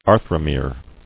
[ar·thro·mere]